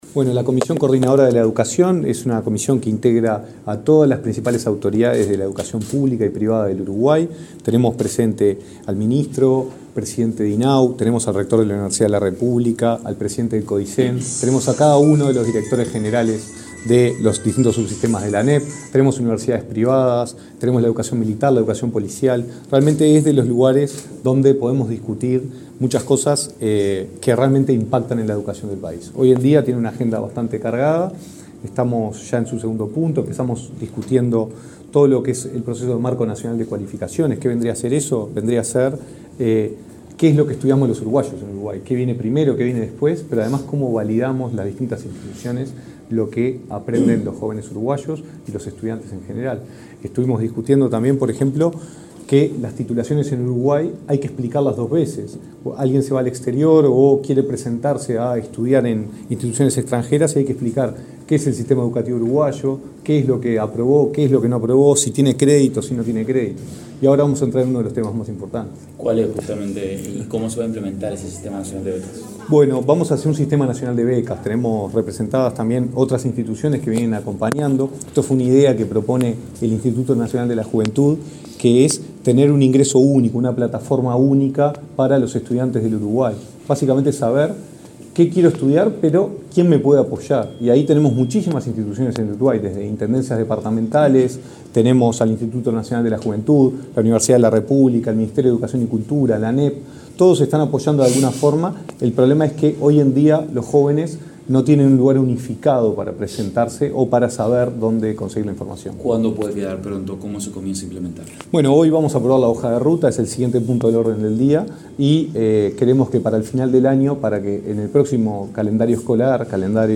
Declaraciones del director nacional de Educación
Declaraciones del director nacional de Educación 24/07/2023 Compartir Facebook X Copiar enlace WhatsApp LinkedIn Este lunes 24, en Montevideo, el director nacional de Educación, Gonzalo Baroni, dialogó con la prensa luego de participar en una sesión de la Comisión Coordinadora Nacional de Educación.